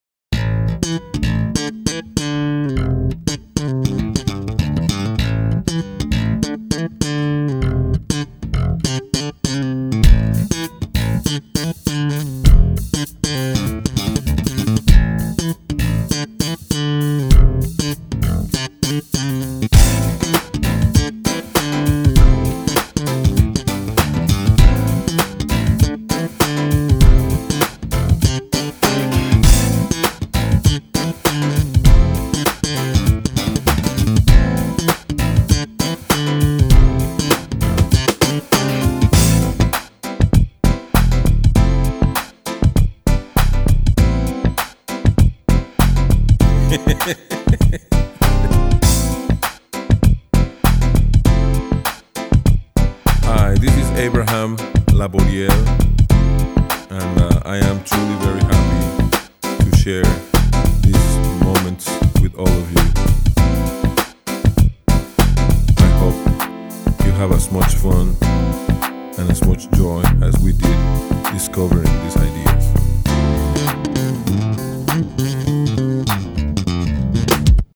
Большое яблоко [Funk]
Остальное рок, есть щепотка блюза.
Бас вроде и есть,а звукового давления от него нет такого ,какое должно быть.